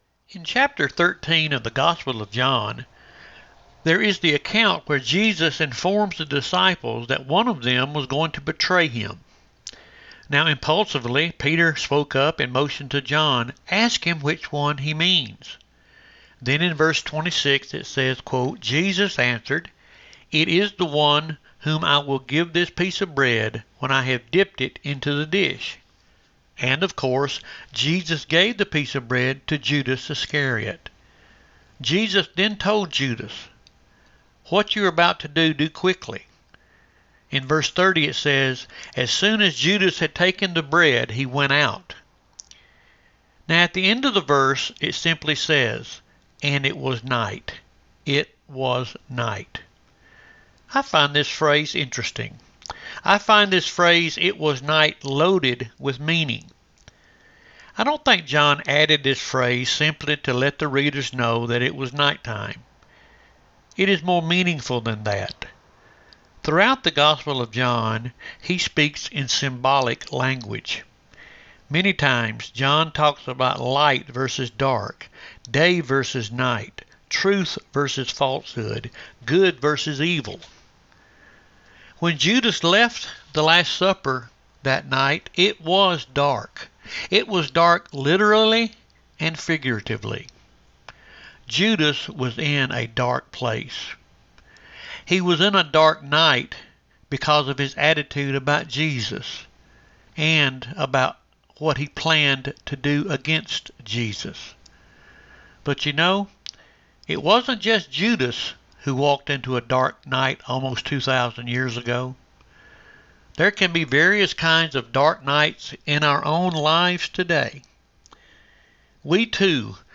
Thursday 8/18 Devotion – Lyerly United Methodist Church